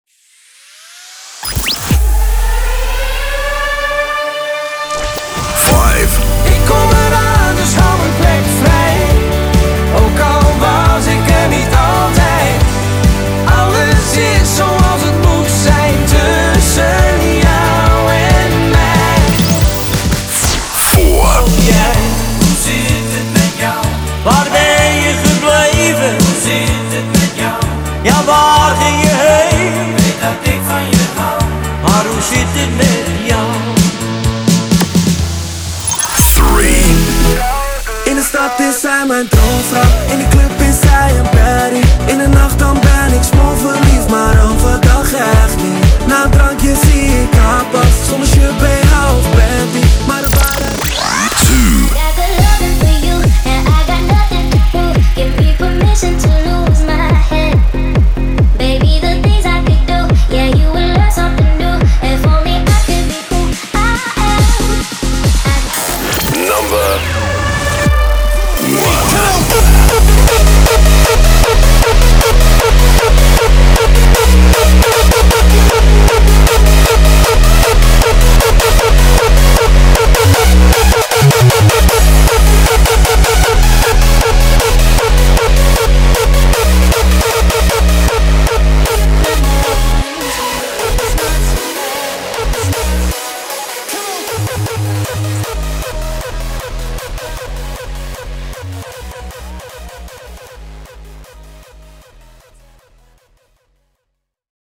De mix en sound effecten heb ik gemaakt in FL Studio.